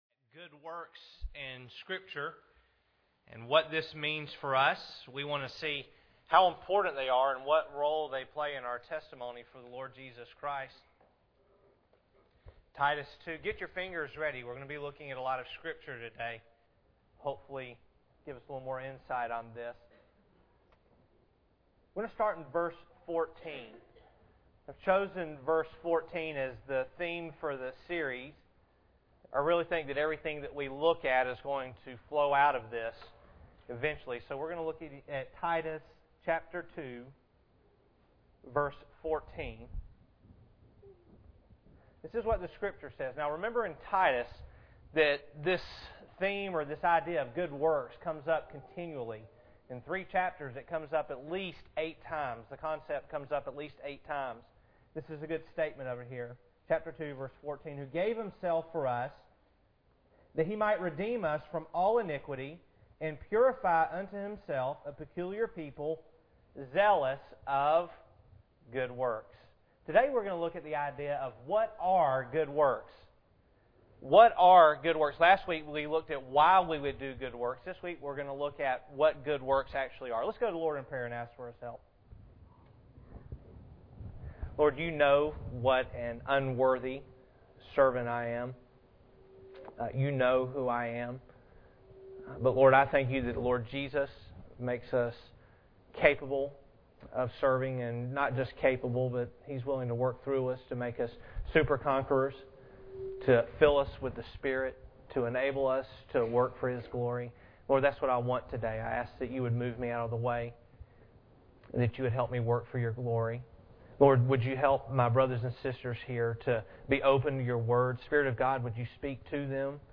Titus 2:14 Service Type: Sunday Morning Bible Text